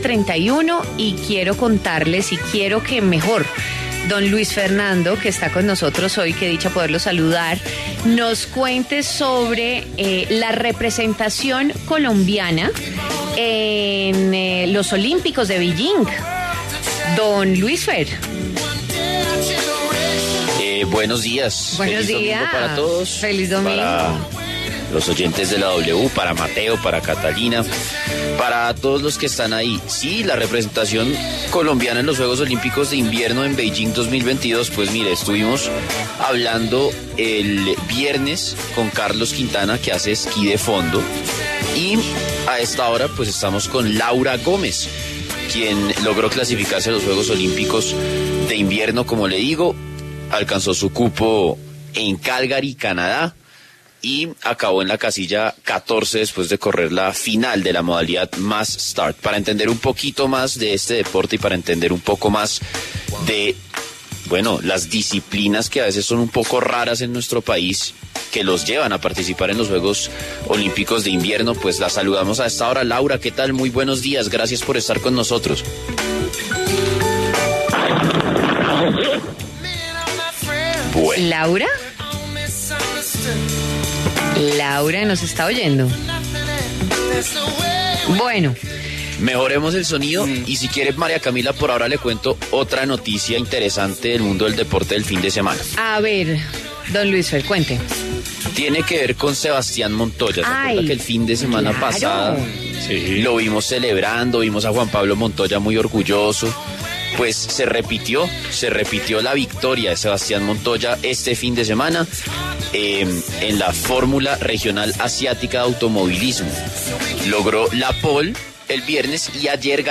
La deportista colombiana pasó por los micrófonos de W Fin de Semana para dar detalles de su competencia.